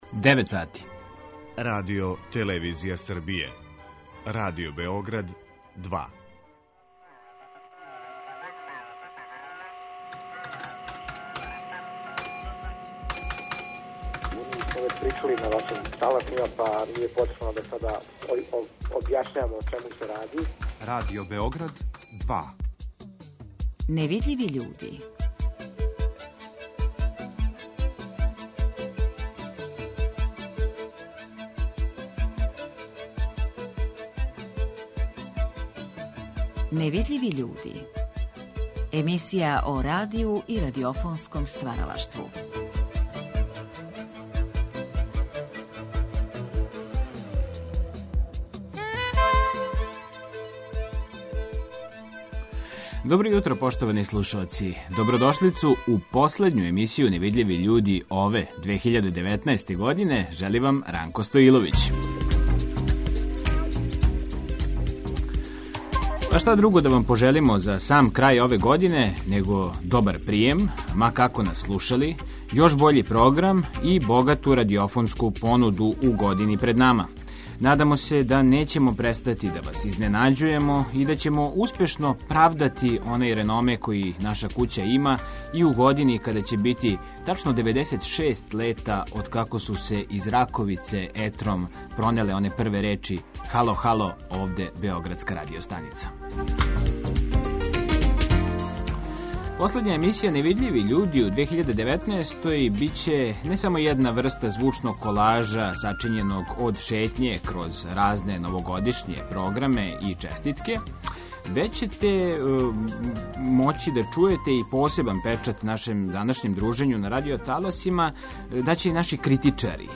Радио Београд је током девет и по деценија постојања тонски бележио и усмене историје грађана наше земље, па су тако звучно похрањена и њихова сећања на новогодишње празнике с почетка прошлог века. Пригодне радио-драме, хумореске, скечеви и вињете бојили су новогодишњи програм на таласима Радио Београда, па ћете чути и избор из ових празничних емисија.